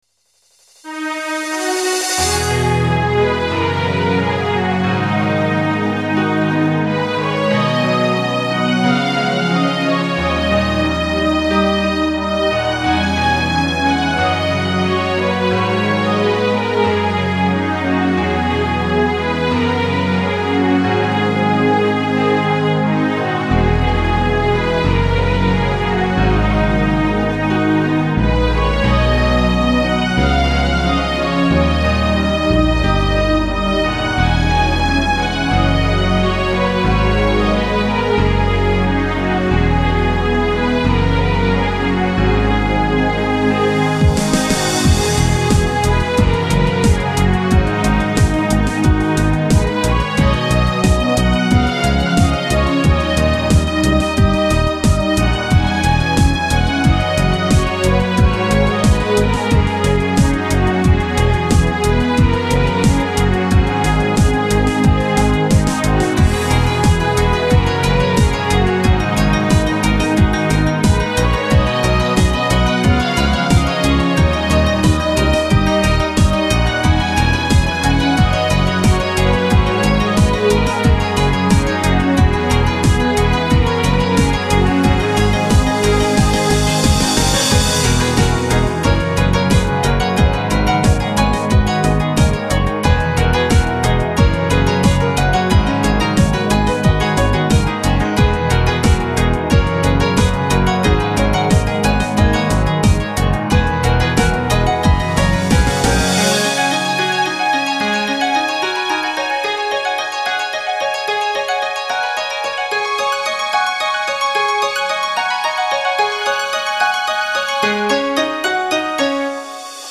久々のアレンジアルバム。